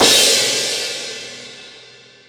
Percs